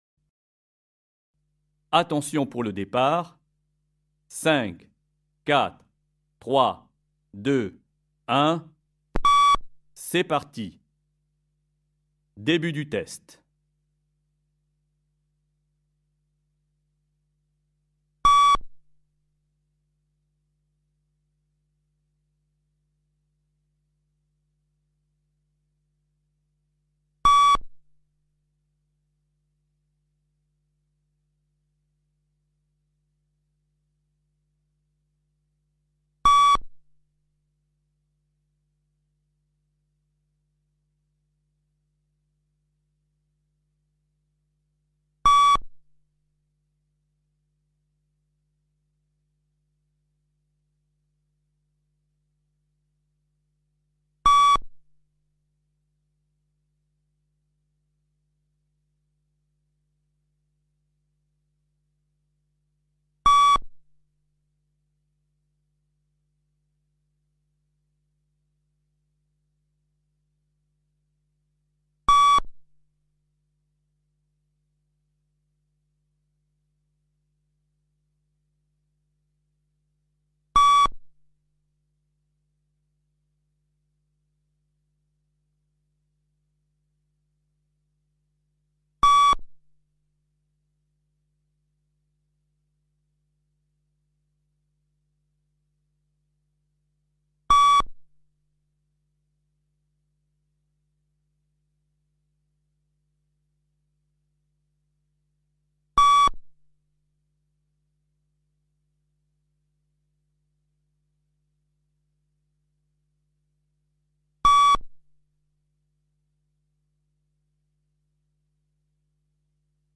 Test du Luc Leger bande son officielle.mp3